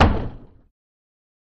Cellar Door Open No Bounce